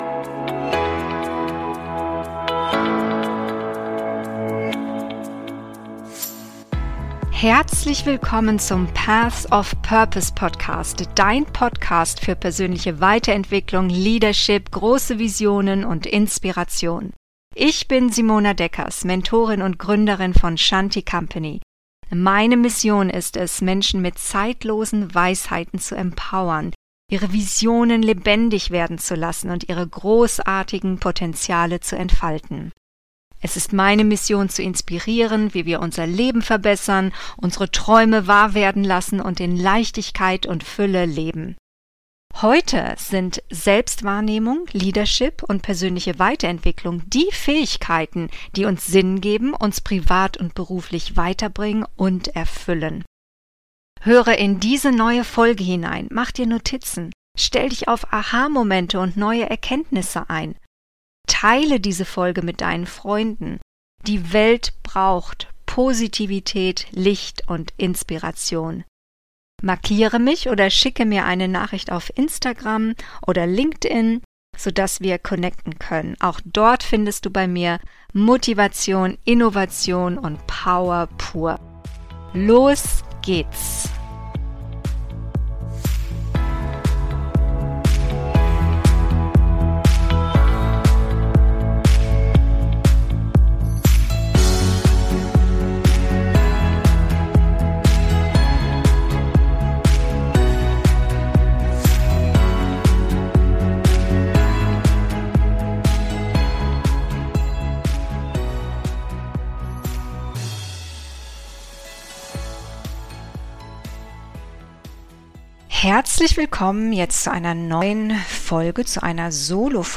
Alle Zeichen stehen auf Neubeginn - Solofolge